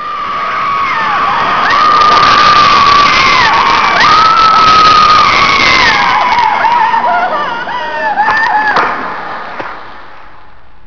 Screamfr
SCREAMFR.wav